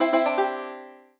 banjo